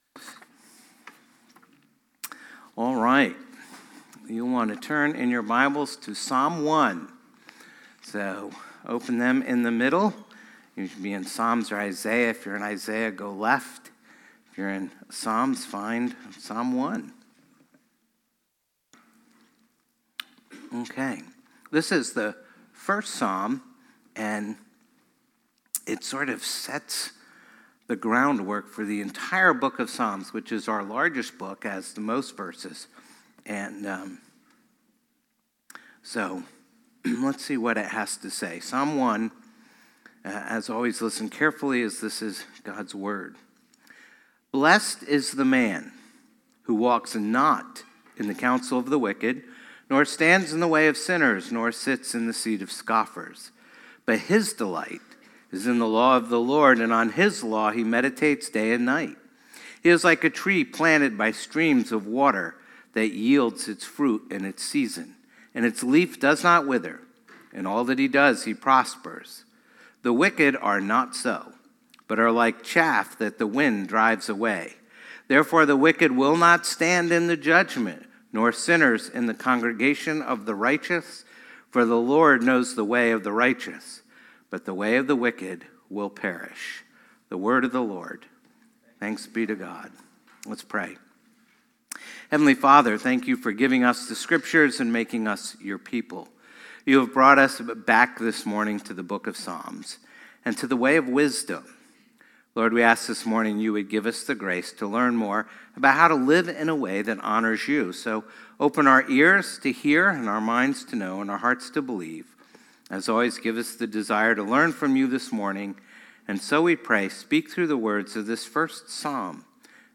phpc-worship-service-5-4-25.mp3